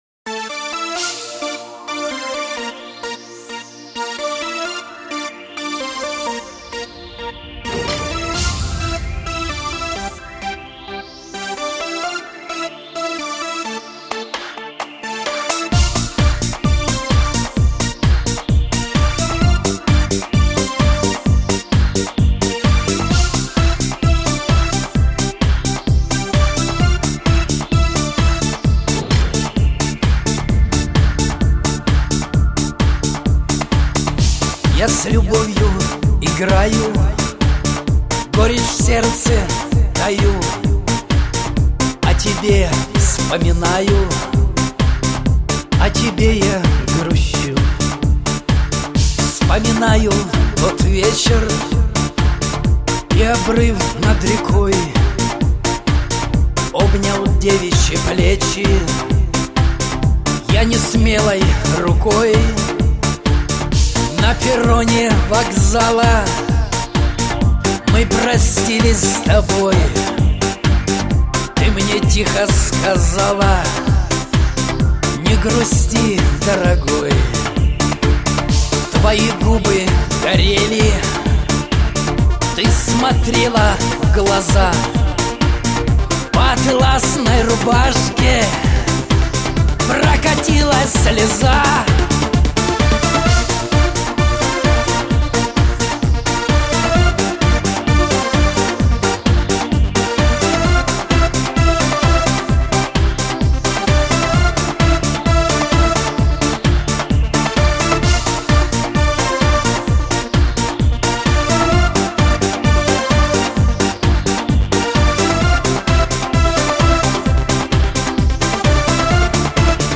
Категория: Шансон